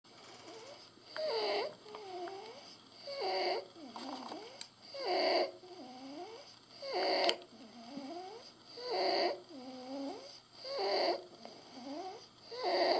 estridor inspiratorios.
Estridor.mp3